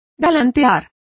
Also find out how galanteo is pronounced correctly.